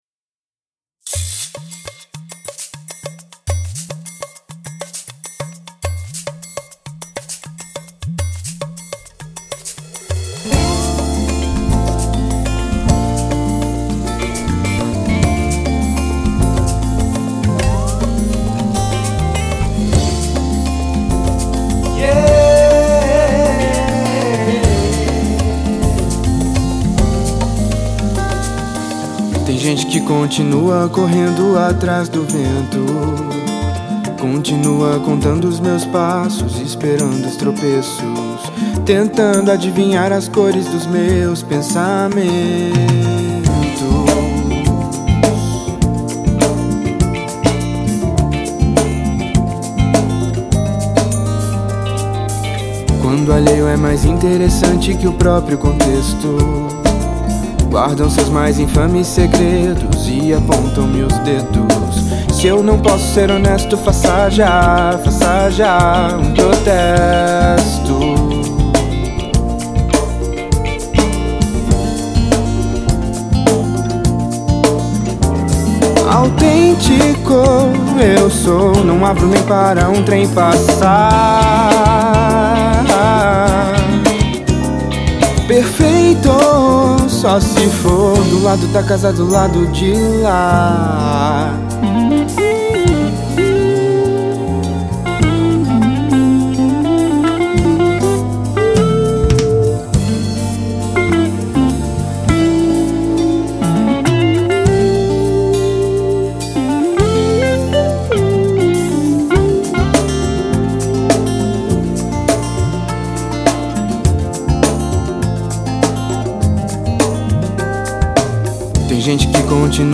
versando através do Jazz, Bossa Nova e MPB